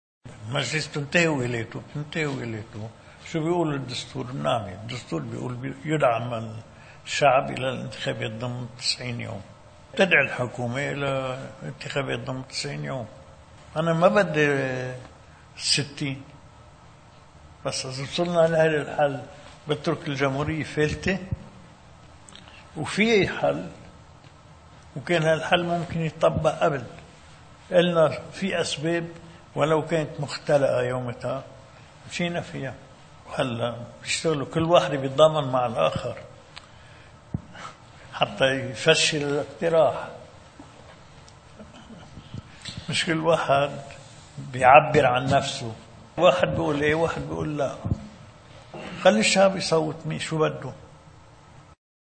مقتطف من حديث الرئيس ميشال عون لوفد الصحافة: